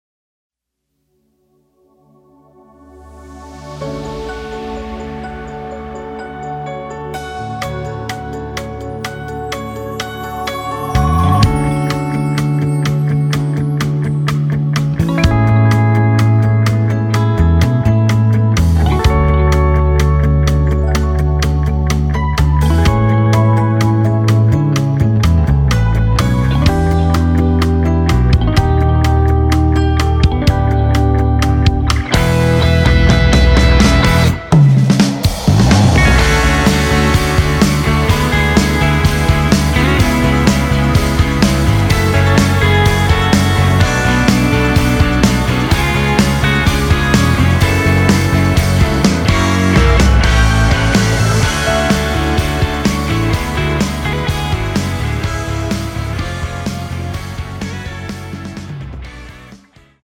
[공식 음원 MR]
앞부분30초, 뒷부분30초씩 편집해서 올려 드리고 있습니다.
중간에 음이 끈어지고 다시 나오는 이유는
위처럼 미리듣기를 만들어서 그렇습니다.